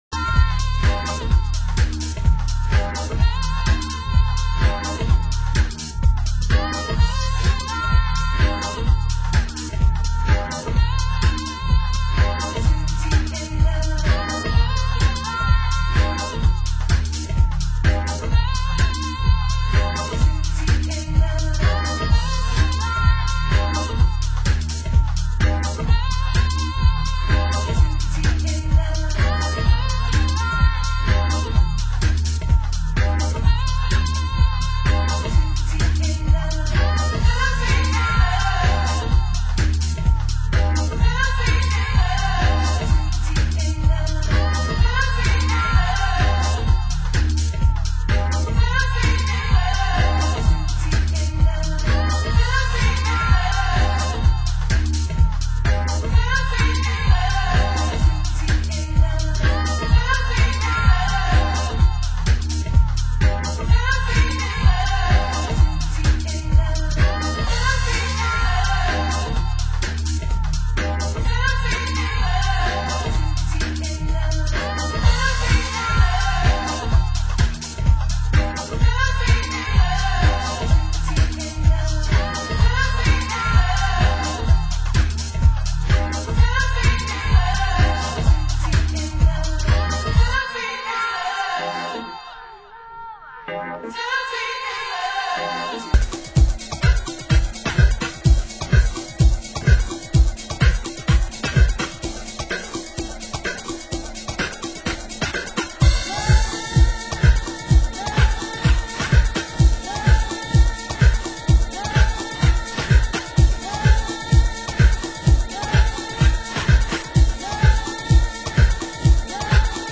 Format: Vinyl 12 Inch
Genre: US House